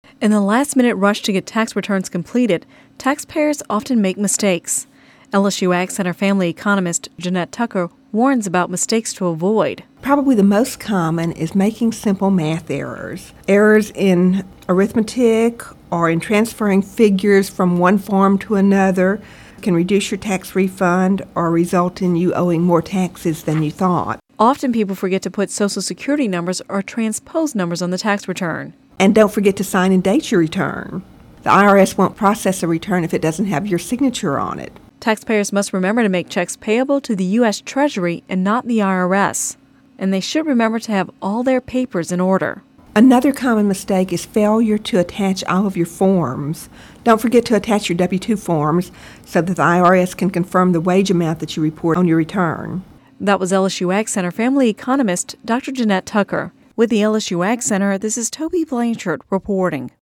(Radio News 03/07/11) In the last minute rush to get tax returns completed, taxpayers often make mistakes.